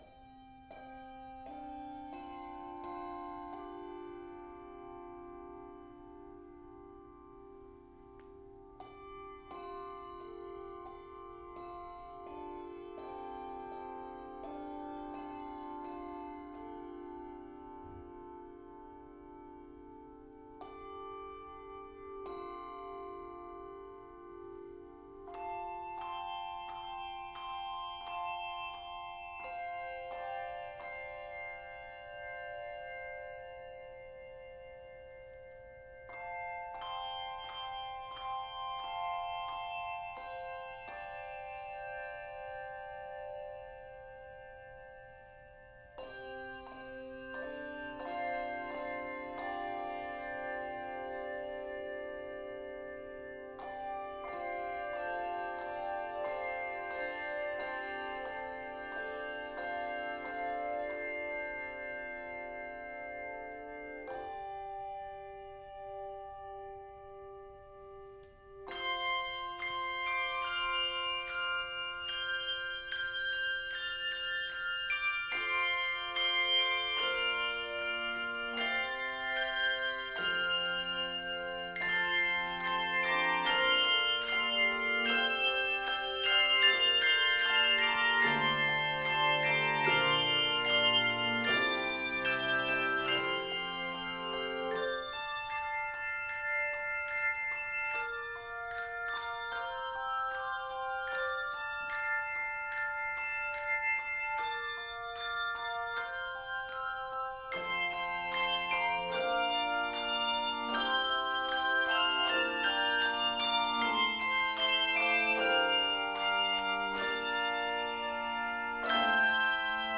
Key of F Major.
Octaves: 3-5